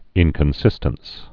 (ĭnkən-sĭstəns)